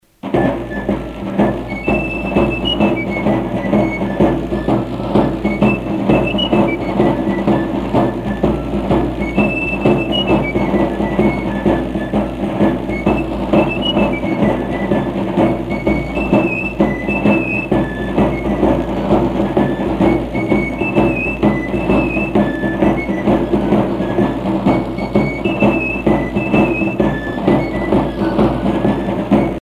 danse : farandole
Pièce musicale éditée